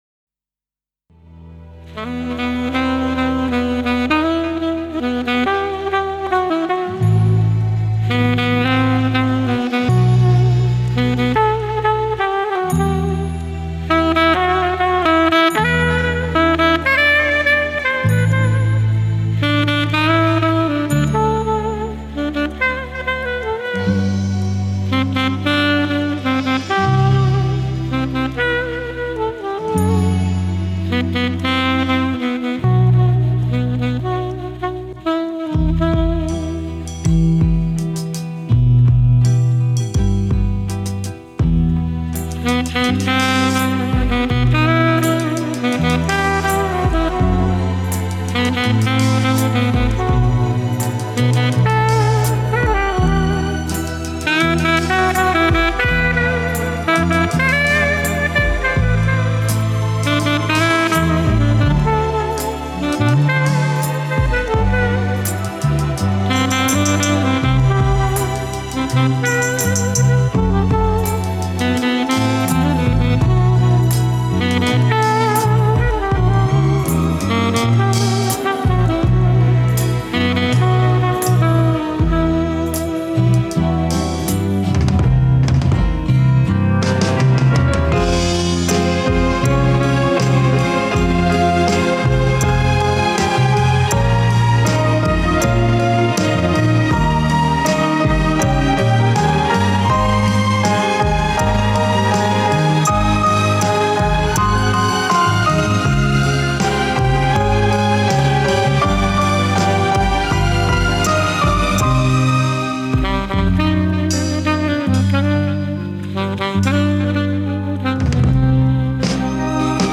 Снежный простор, спокойствие и саксофон.....ну полный релакс:))!